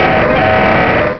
pokeemmo / sound / direct_sound_samples / cries / aggron.wav